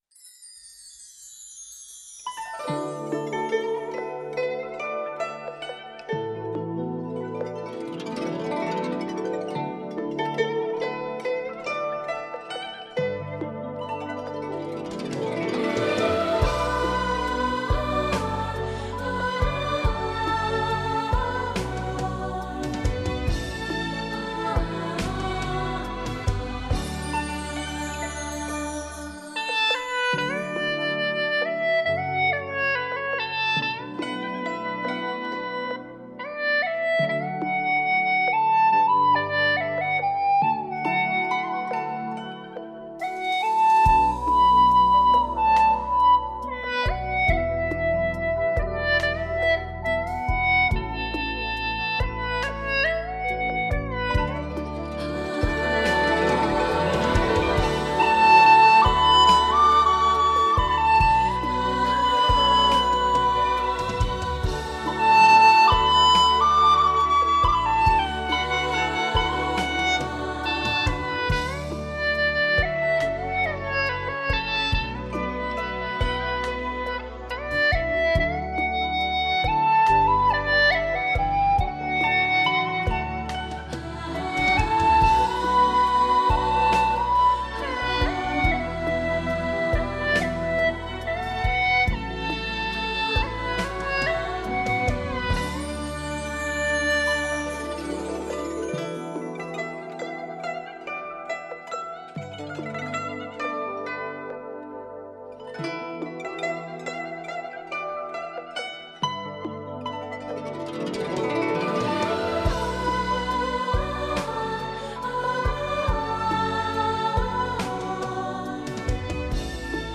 音乐风格：古典
演奏乐器：葫芦丝